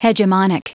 Prononciation du mot : hegemonic
hegemonic.wav